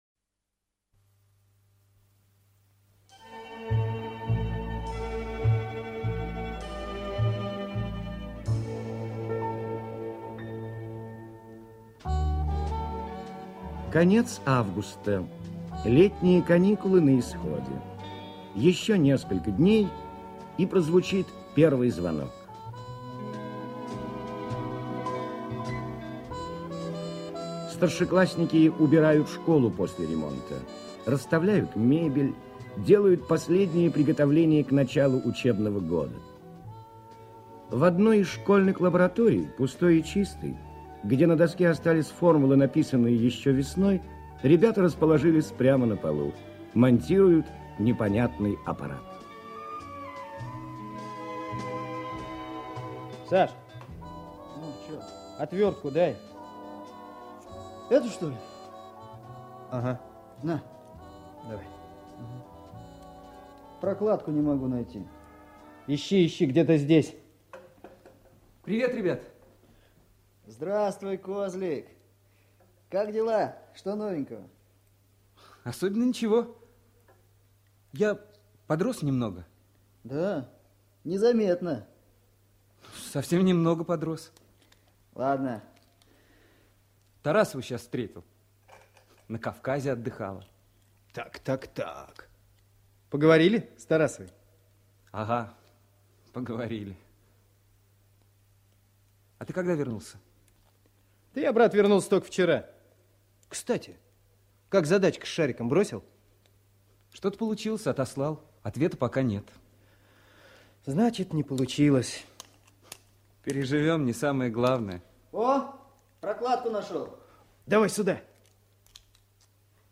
Мужчина семнадцати лет - аудио рассказ Дворецкого